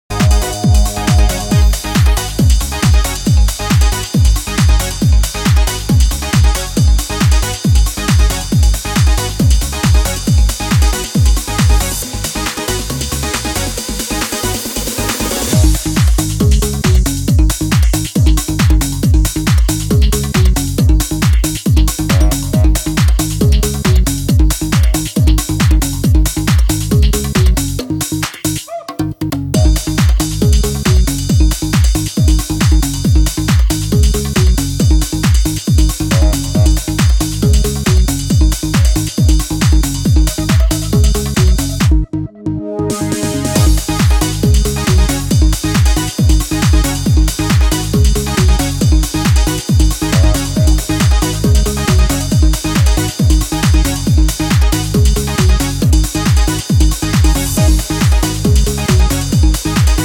セカンド・サマー・オブ・ラヴ期アシッド・ハウスなベースラインが渋い